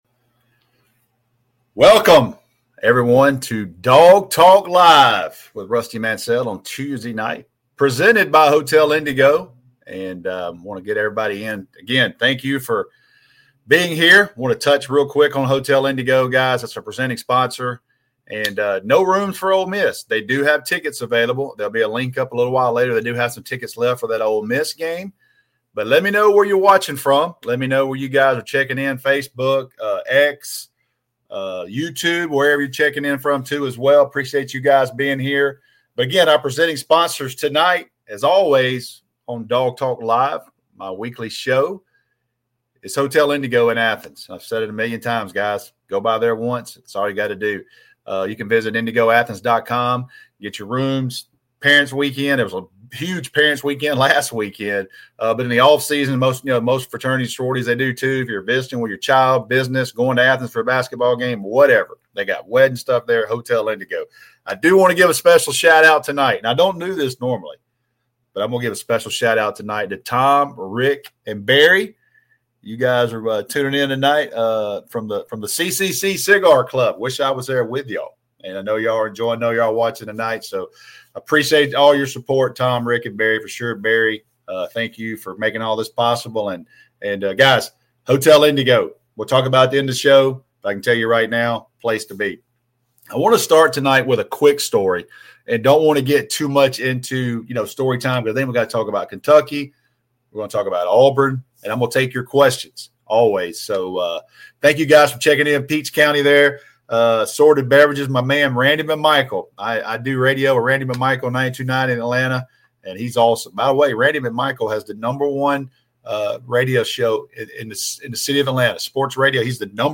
He previews the upcoming showdown with Auburn, shares some stories, and, as always, takes questions from live viewers.